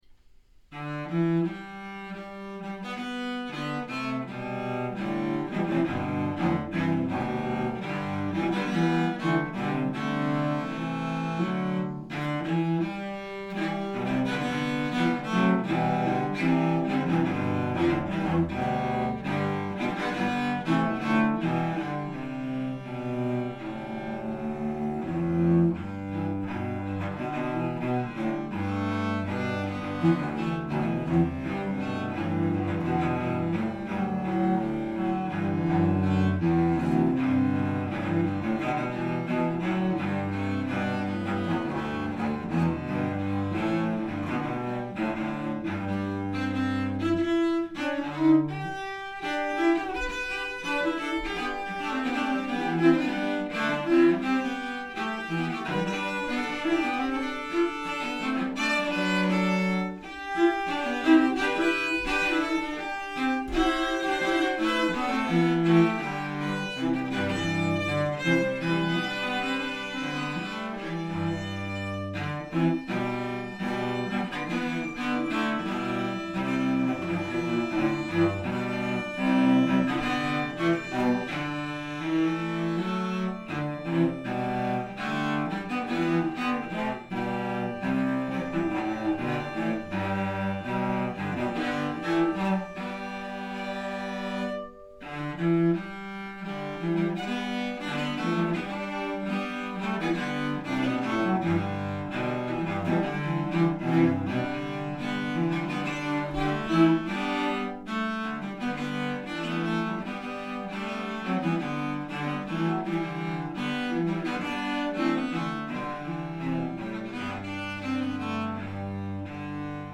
traditional American spiritual